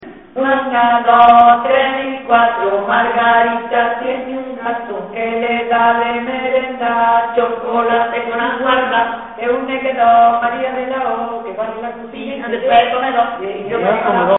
Materia / geográfico / evento: Canciones de comba Icono con lupa
Zafarraya (Granada) Icono con lupa
Secciones - Biblioteca de Voces - Cultura oral